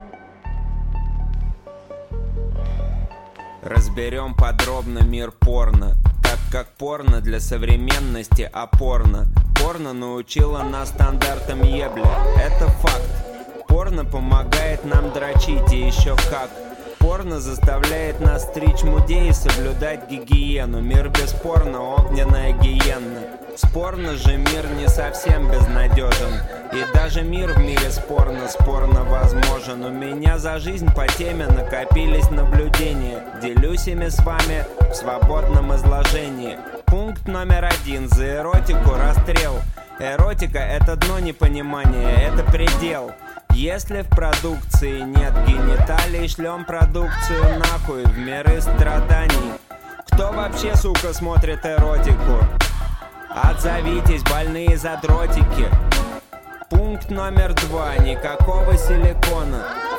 Материал был записан с весны по осень 2007 года в Москве.